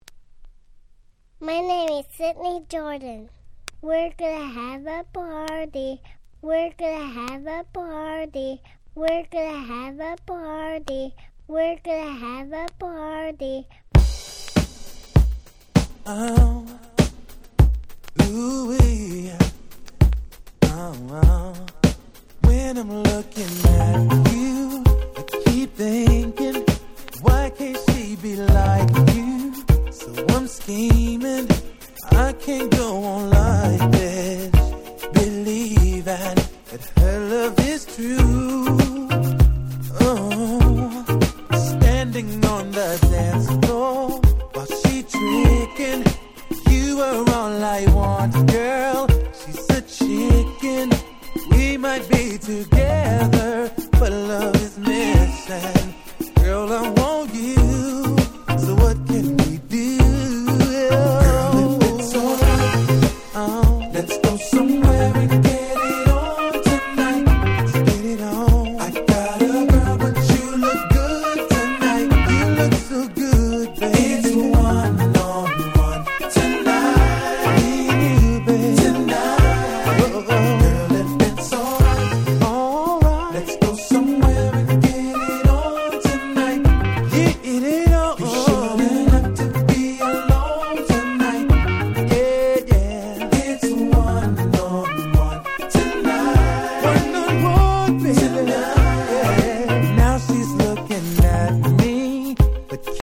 00' Big Hit R&B Album !!